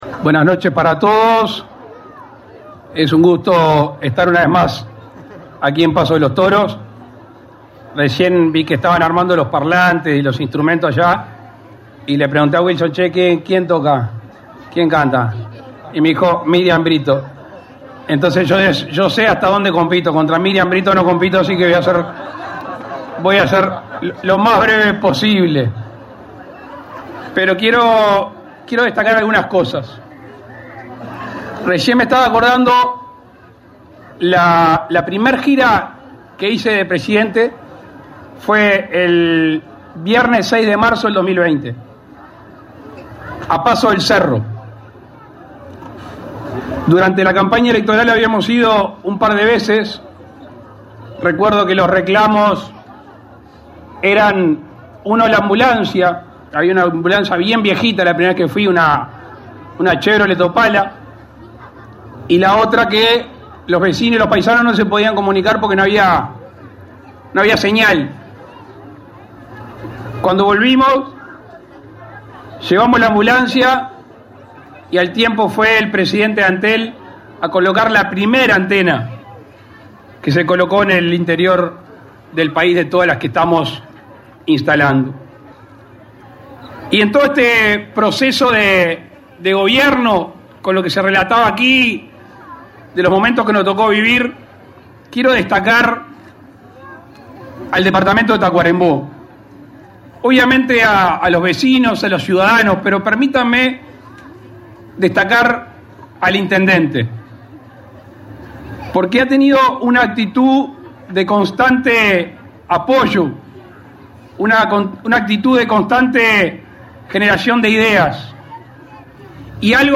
Palabras del presidente de la República, Luis Lacalle Pou, en Paso de los Toros
El presidente de la República, Luis Lacalle Pou, participó este 13 de setiembre en la inauguración de la terminal de ómnibus en Paso de los Toros.